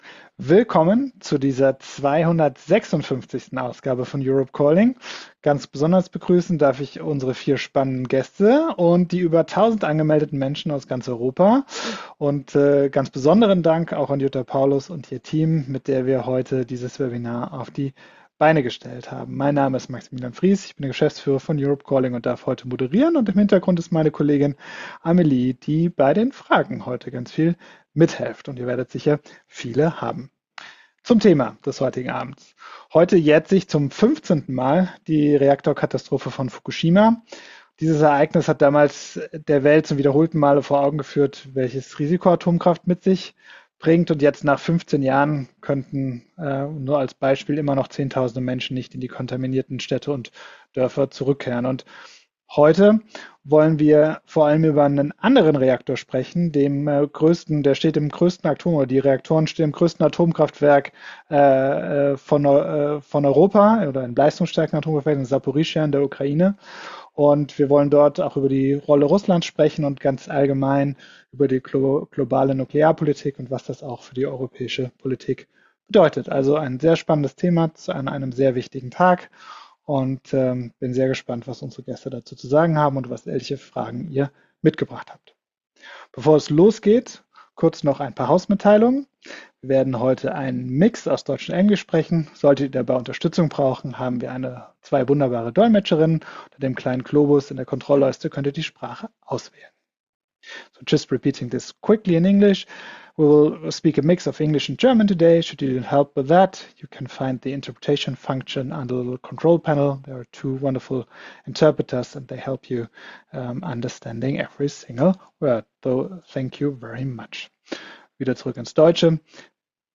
Aufzeichnung der 256. Ausgabe von Europe Calling. Am 11.03.2026 in Kooperation mit Jutta Paulus, MEP.